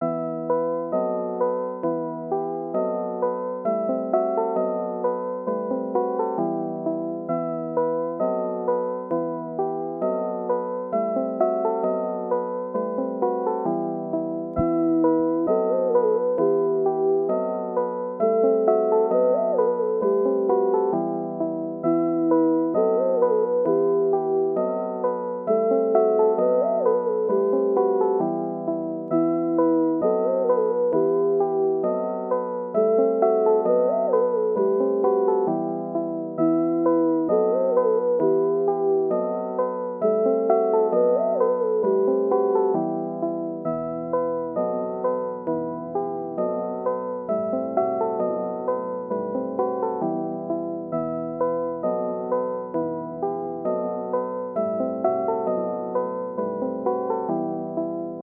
moon_light_66bpm_oz.wav